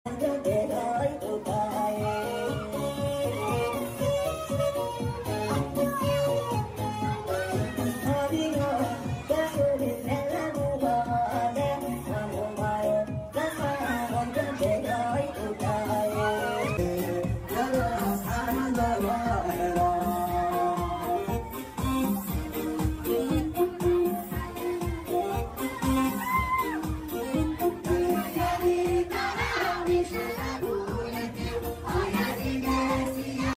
Dhaanto